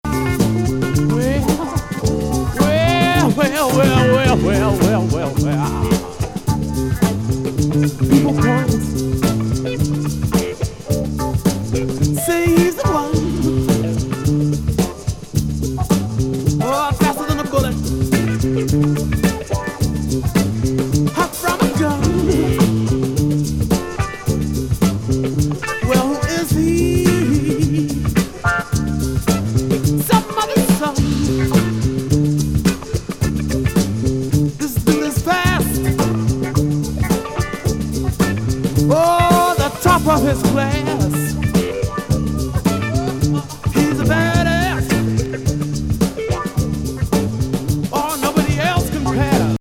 エレクトロ・シーケンスなタイトル・